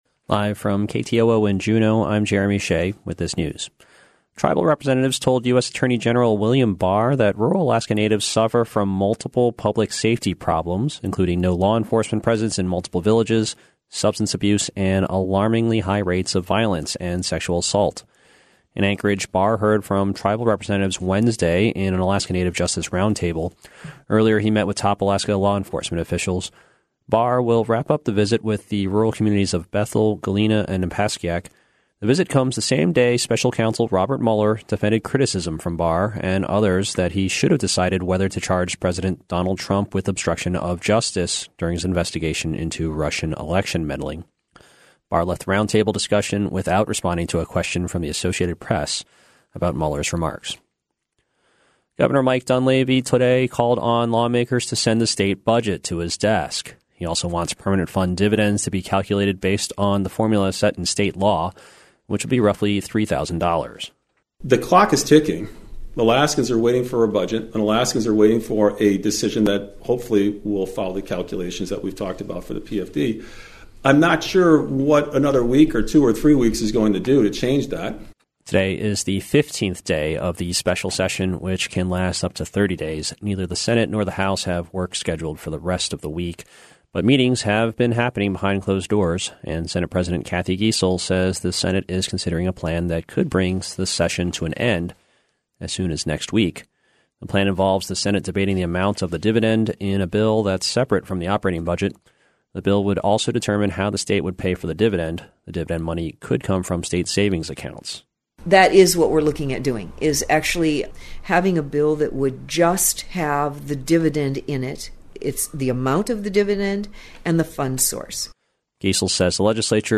Newscast - Wednesday, May 29, 2019
In this newscast: U.S. Attorney General William Barr learns about rural Alaska Natives public safety issues, the governor and legislature continue debating the permanent fund dividend in the special session, recreational gold dredging picks up near Sheep Creek, reindeer used for research and unofficial tourist attraction at UAF will be relocated, U.S. Sen. Dan Sullivan seeks tariff relief for Alaska seafood products, and a snippet of the choral ensemble Queens' Red Carpet Concert.